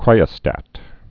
(krīə-stăt)